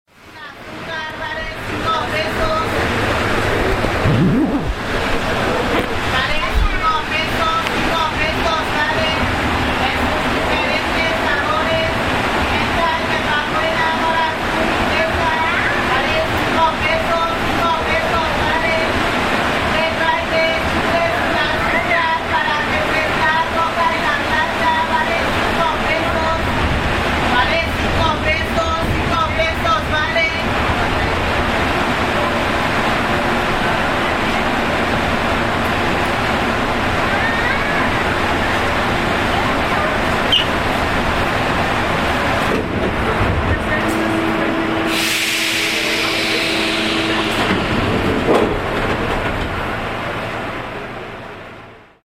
Vendedora en el metro
Los invitamos a realizar un breve recorrido en el metro de la ciudad de Mexico, donde entre otros sonidos está la de una vendedora, que forma parte de los sonidos cotidianos de este lugar.
Equipo: Grabadora Sony ICD-UX80 Stereo